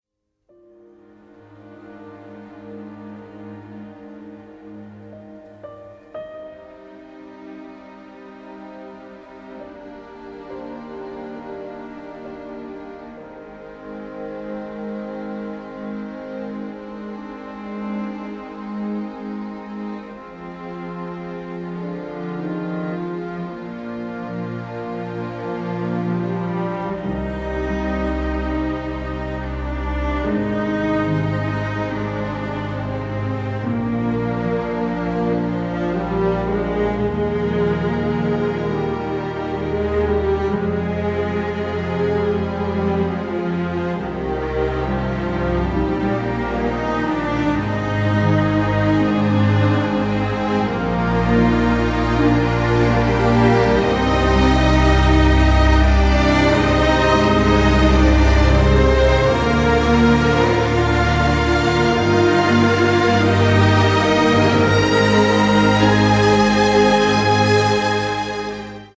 a harrowing, incredibly emotive score
Recorded at Abbey Road Studios